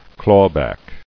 [claw·back]